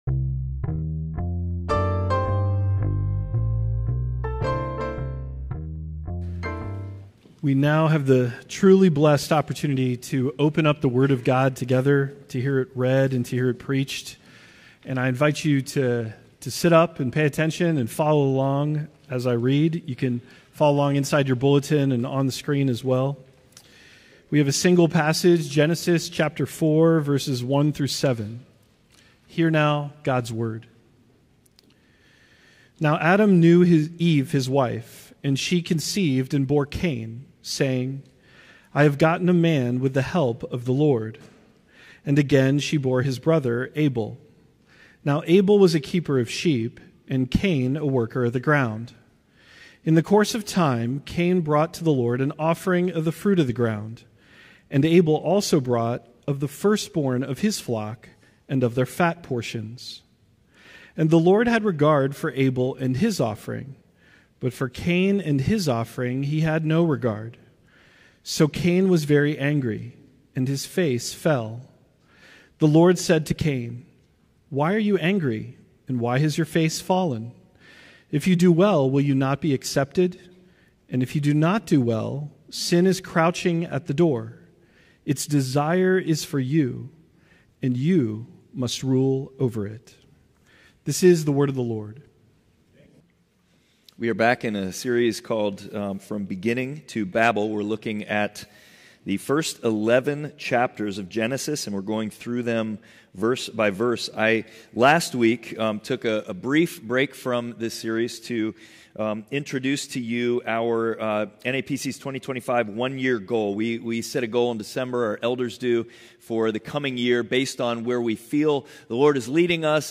Passage: Genesis 4:1-7 Service Type: Sunday Worship